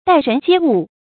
注音：ㄉㄞˋ ㄖㄣˊ ㄐㄧㄝ ㄨˋ
待人接物的讀法